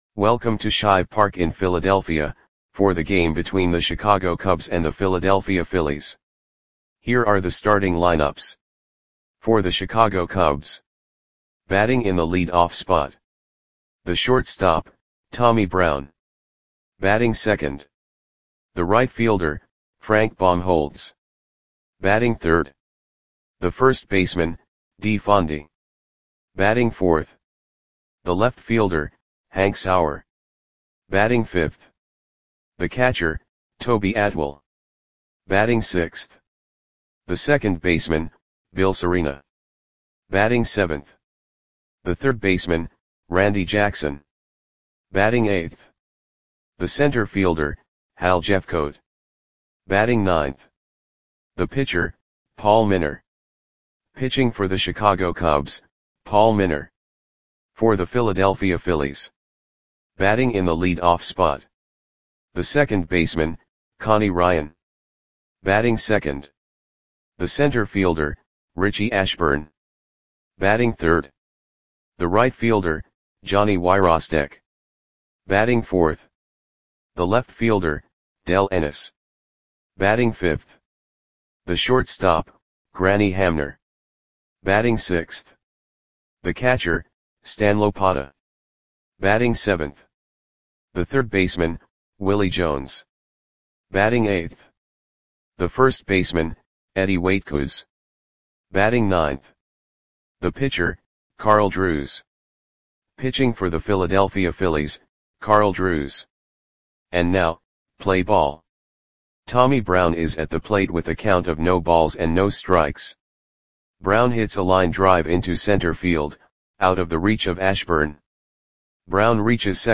Audio Play-by-Play for Philadelphia Phillies on September 12, 1952
Click the button below to listen to the audio play-by-play.